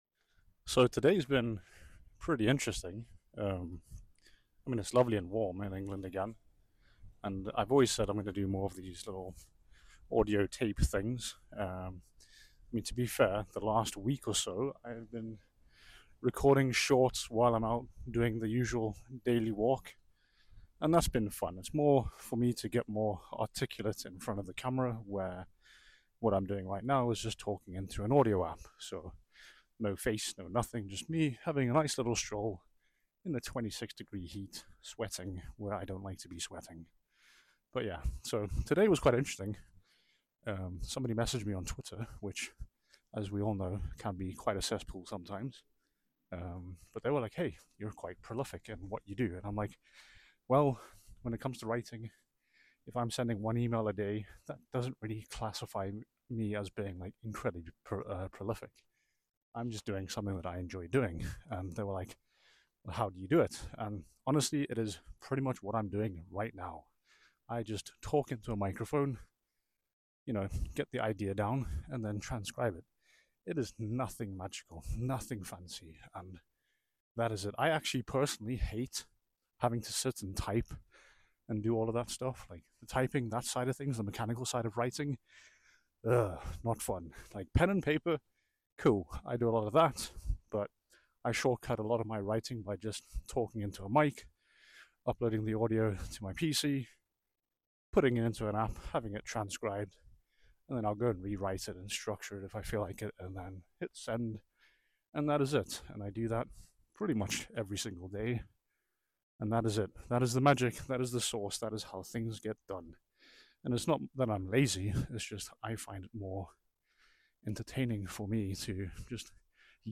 So here’s the next short little ramble. It’s more about my process and how you can use it too if you’re interested.